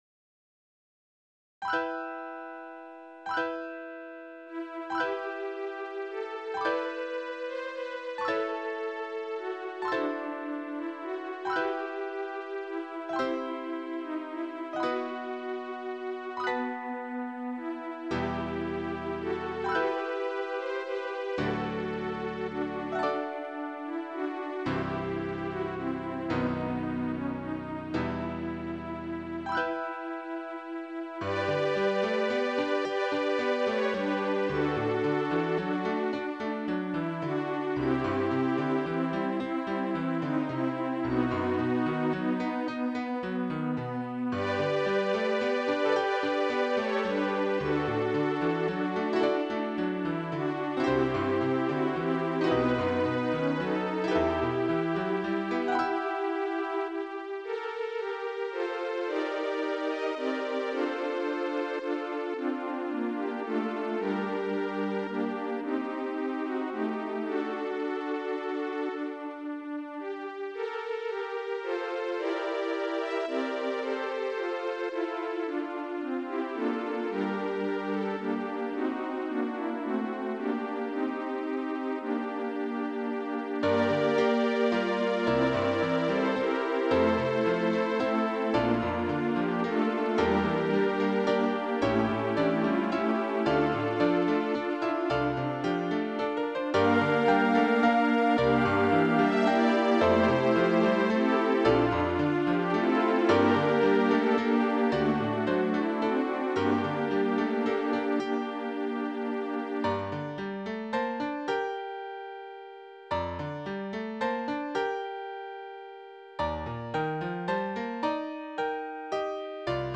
has an optional 4-hand piano accompaniment at the end.
Voicing/Instrumentation: SSAA
Piano 4-hand as accompaniment